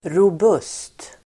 Uttal: [rob'us:t]